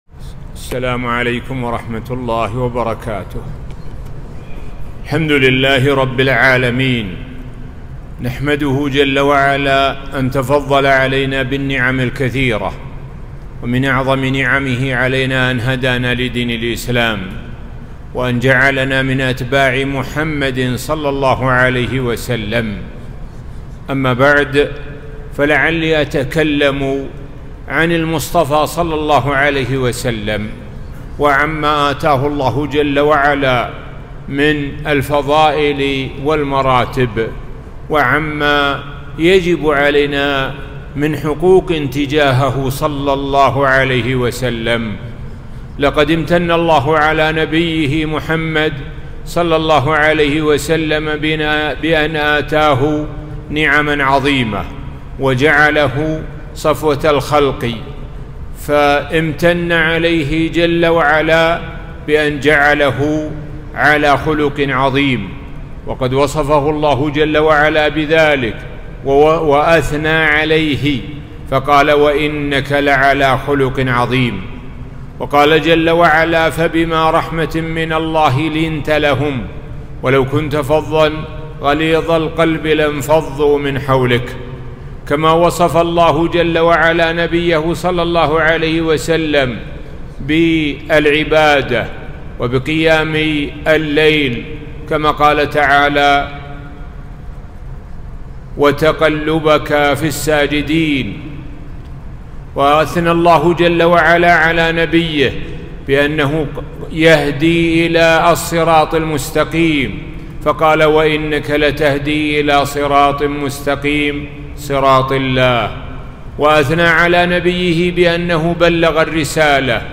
محاضرة - حقوق المصطفى ﷺ.